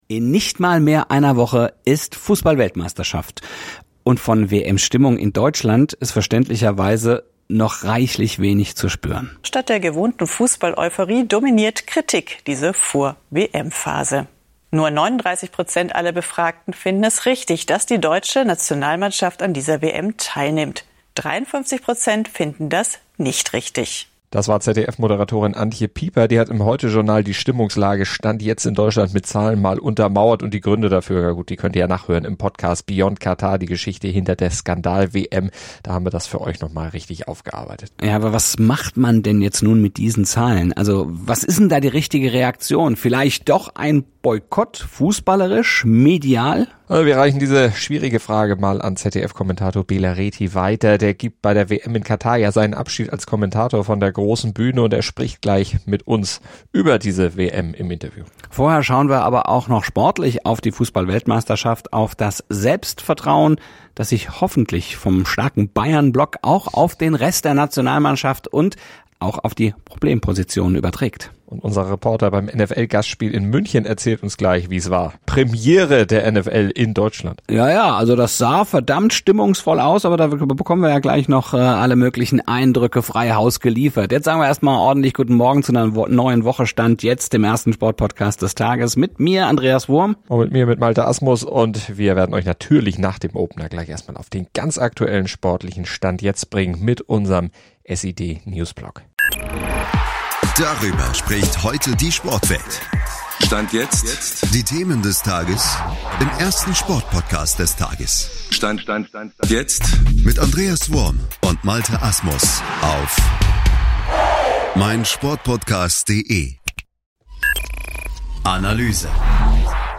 Im Interview erzählt er, wie groß seine Bauchschmerzen sind, in die Wüste zu fahren und warum er einen medialen Boykott nicht für zielführend hält.